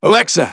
synthetic-wakewords
ovos-tts-plugin-deepponies_Engineer_en.wav